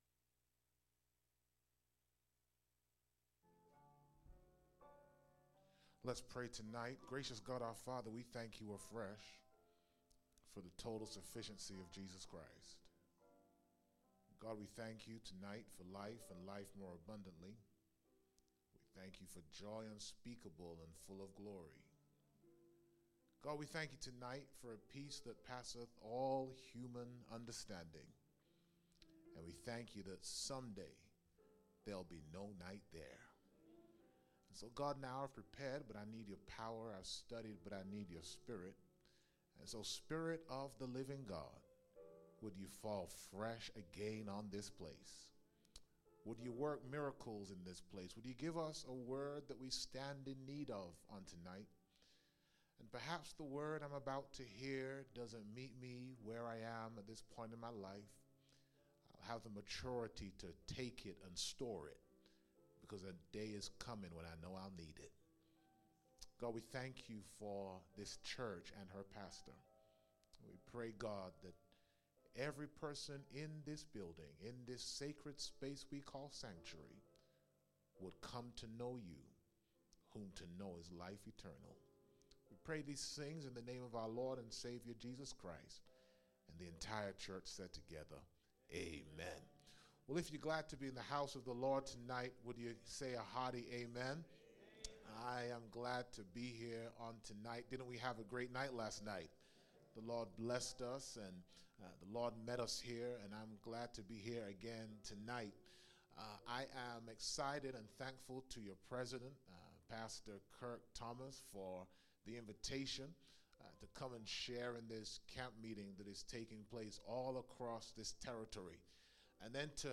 Monday Night presentation from the SEC Camp Meeting 2024